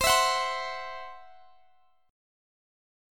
Listen to CMb5 strummed